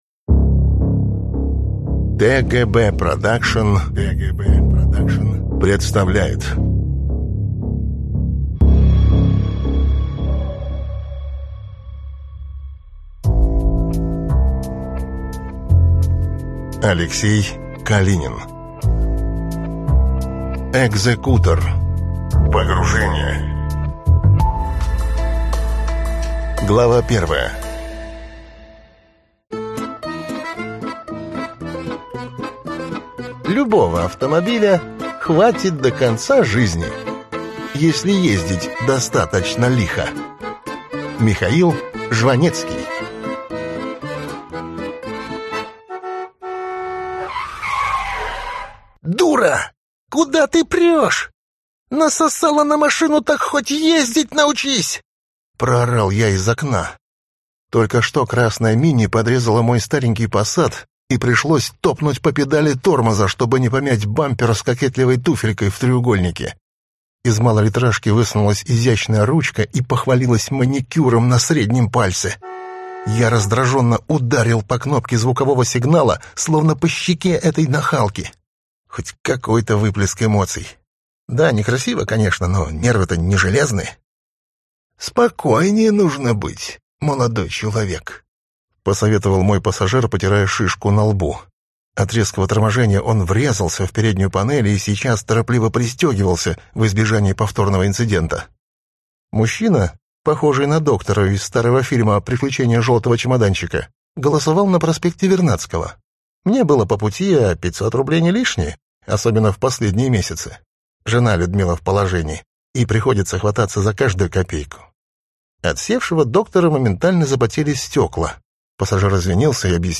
Аудиокнига Экзекутор. Погружение | Библиотека аудиокниг